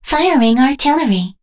Kara-Vox_Skill4.wav